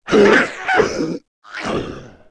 Index of /App/sound/monster/misterious_diseased_spear
dead_1.wav